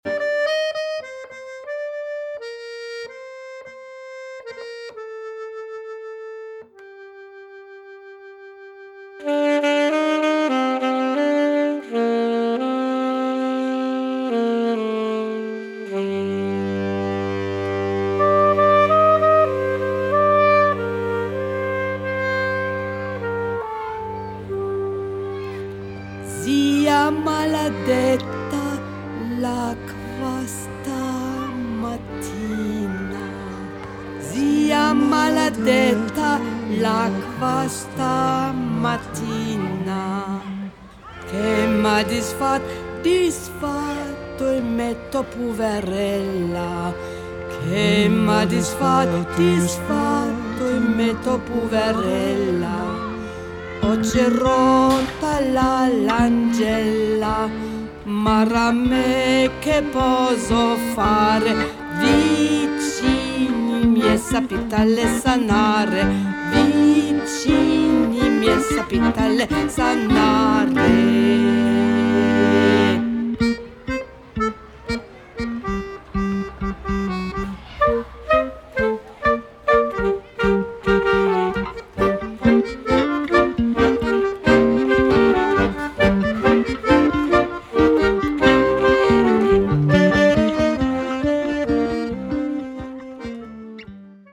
M&T: Traditional Italien
Bassgitarren
Background Gesang